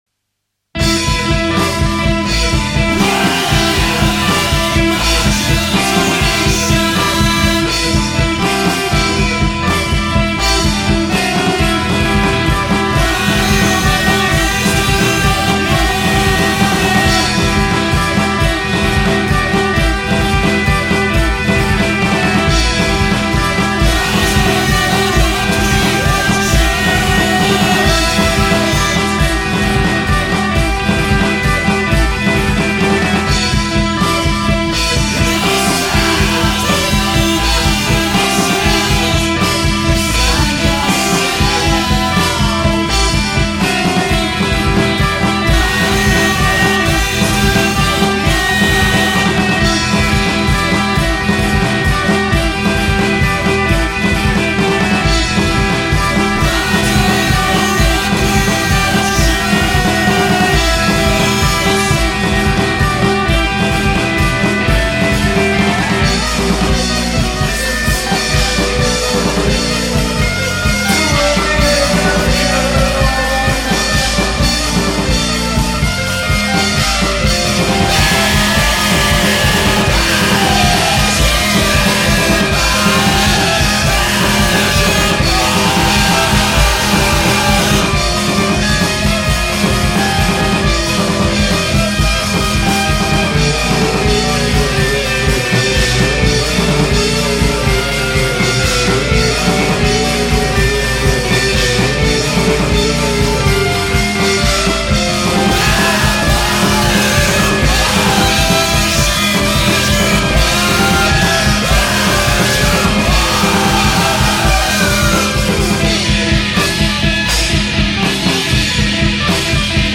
demo
cheap recording
from the rehersal room